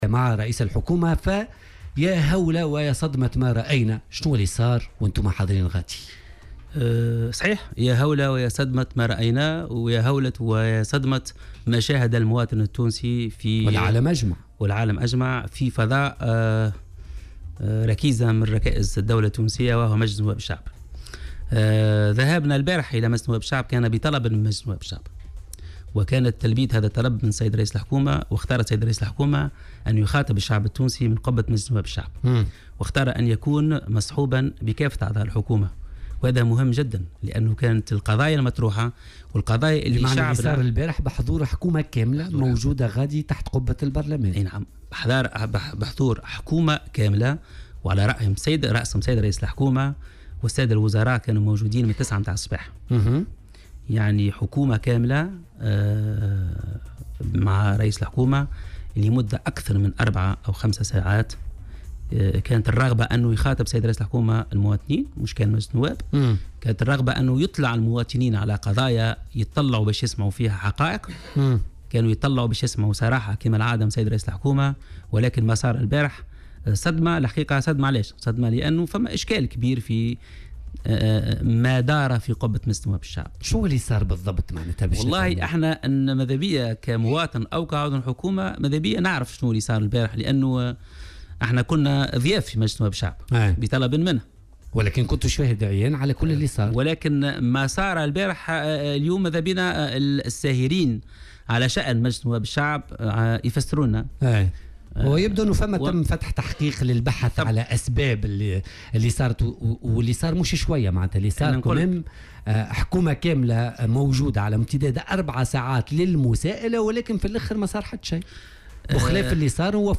اعتبر وزير أملاك الدولة والشؤون العقارية هادي ماكني في حوار مسجل مع "بوليتيكا" تم بثّه اليوم الاثنين على "الجوهرة اف أم" أنّ ما حصل مخجل وفضيحة وصدمة، وفق تعبيره في تعليقه على الفوضى التي سادت مجلس نواب الشعب مما أدى إلى الغاء جلسة عامة كانت مقررة مع رئيس الحكومة يوسف الشاهد، الخميس الماضي.